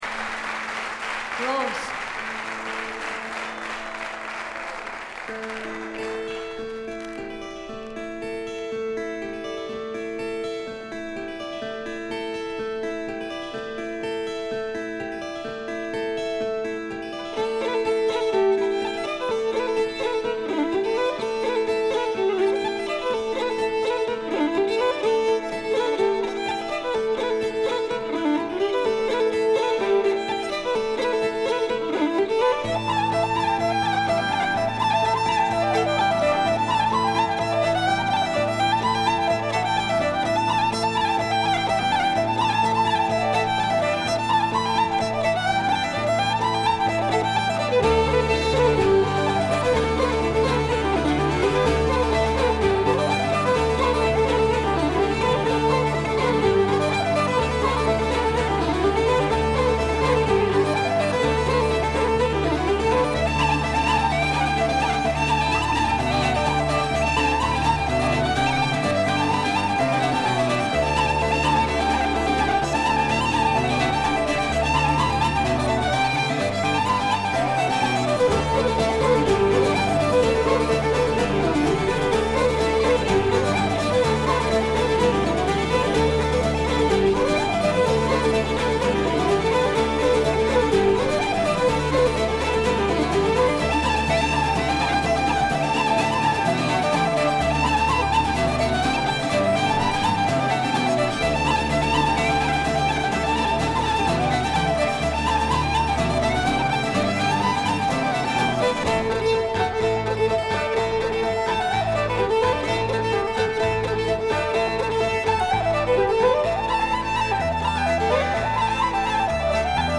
わずかなノイズ感のみ。
試聴曲は現品からの取り込み音源です。